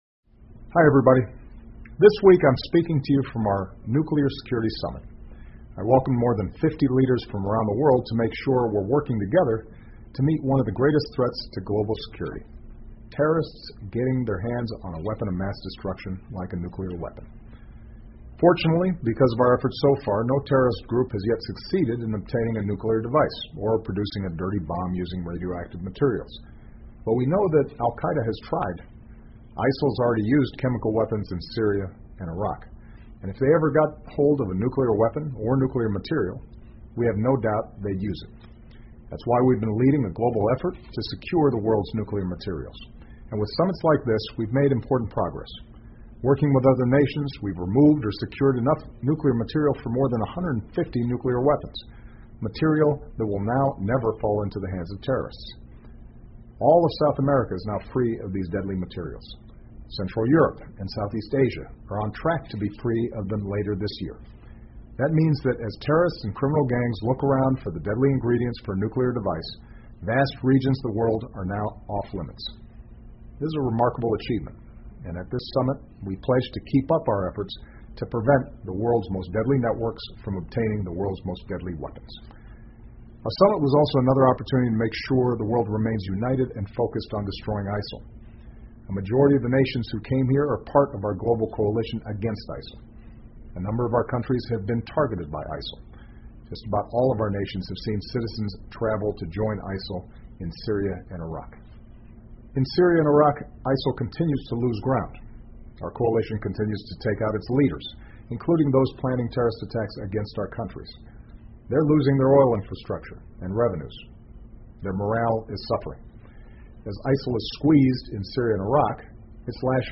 奥巴马每周电视讲话：总统呼吁维护世界和平与安全 远离核恐怖主义 听力文件下载—在线英语听力室